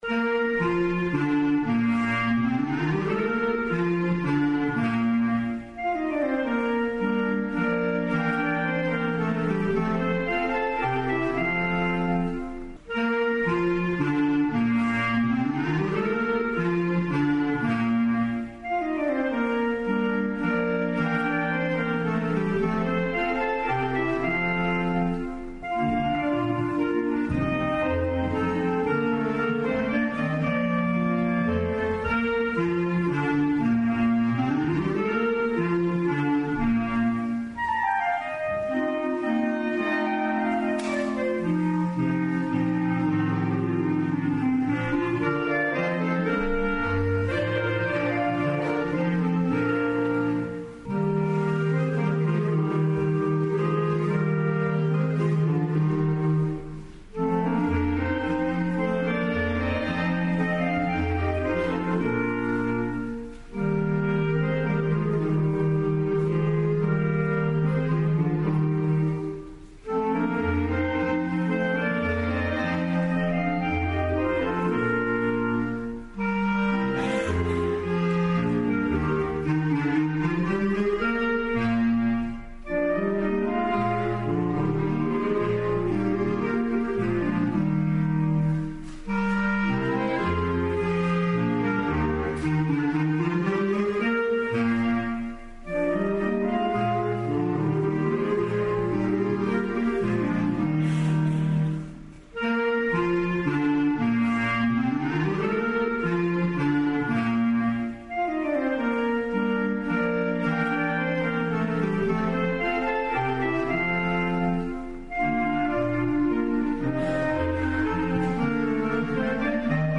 Adventmusik in Weißenbach
Am 1. Adventsonntag 2013 fand der "16. musikalische Advent" in der Kirche Weißenbach/Liezen statt.
Menuett; siehe Tonbeispiele) wurde u.a. auch das Admonter Krippenlied gespielt.